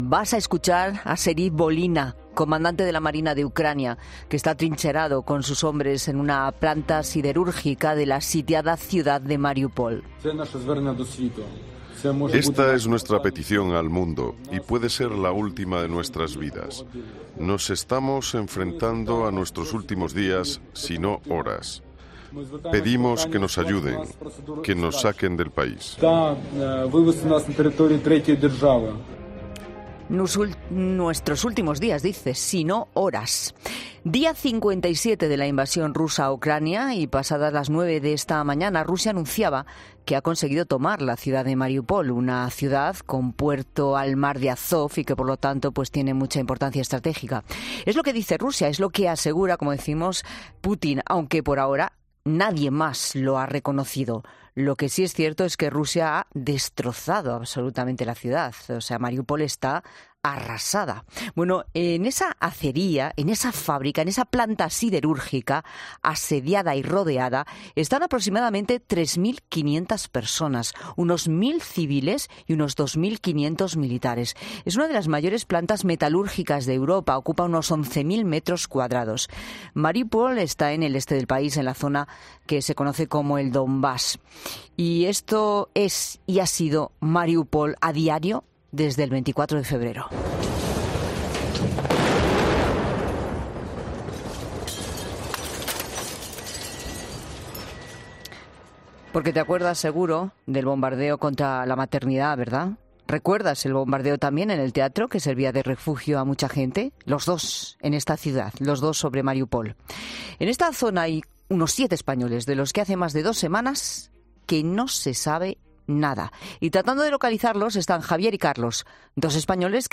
Lorenzo Silva, escritor y columnista, ha reflexionado sobre Mariúpol y el ejemplo de coraje que dan los defensores de la ciudad.